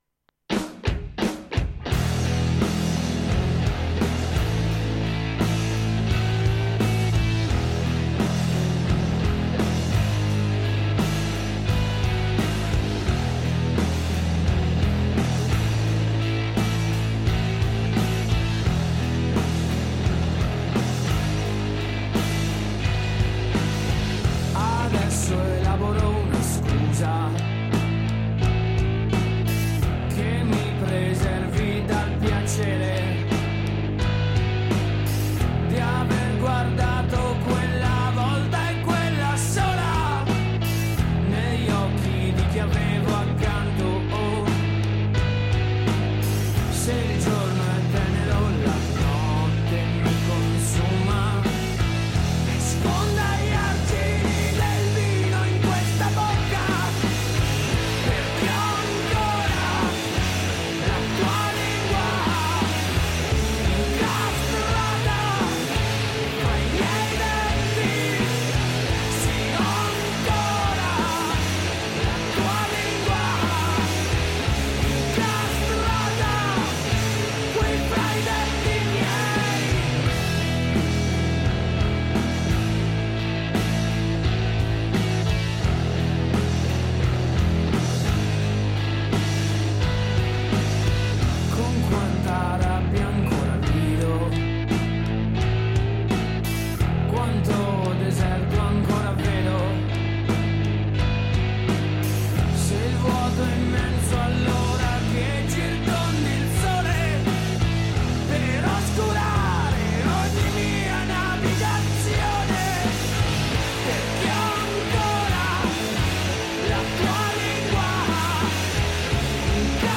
intervista-fask-rocktrotter-26-4-21.mp3